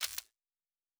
pgs/Assets/Audio/Sci-Fi Sounds/Weapons/Weapon 16 Foley 3 (Laser).wav at master
Weapon 16 Foley 3 (Laser).wav